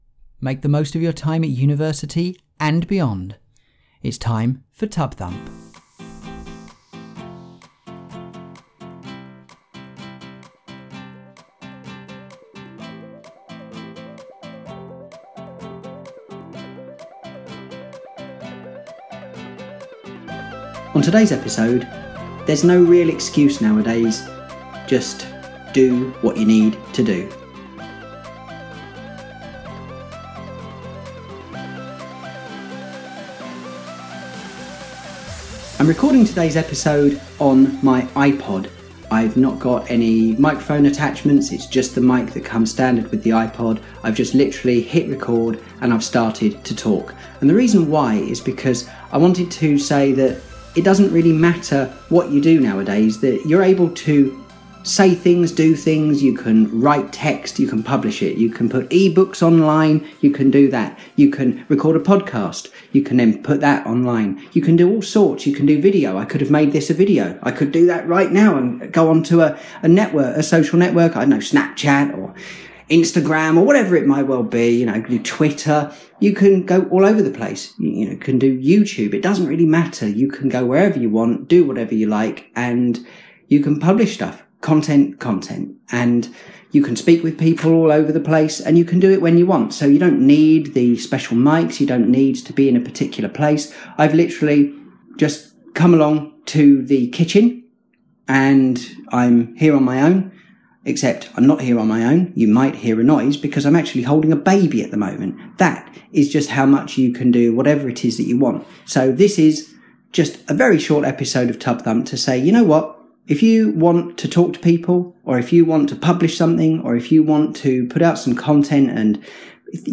“The kitchen has a lot of echo,” I thought.
• 01:30 – If I can record in a kitchen with an echo, on a phone or entertainment device, when holding a baby, what production value issues are stopping you?